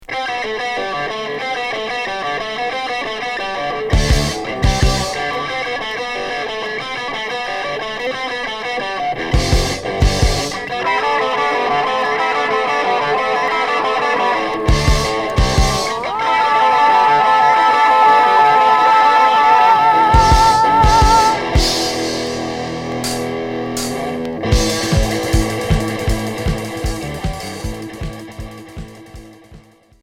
Hard rock